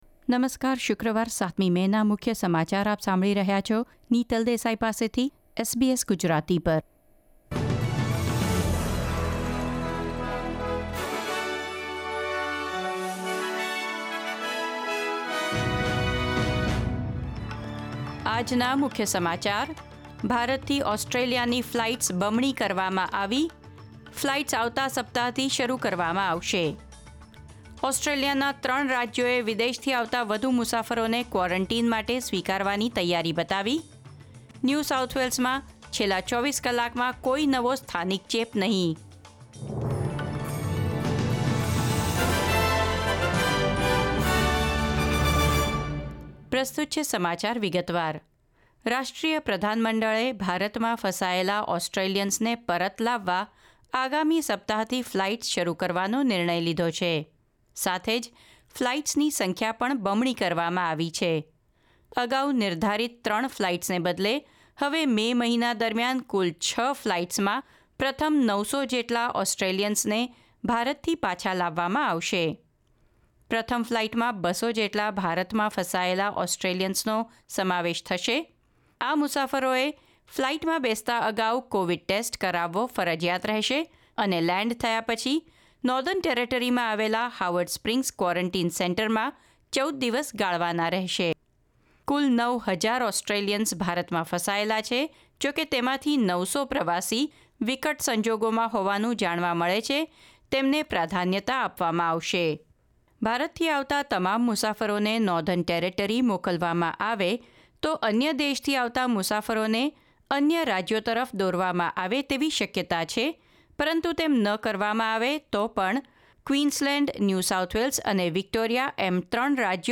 SBS Gujarati News Bulletin 7 May 2021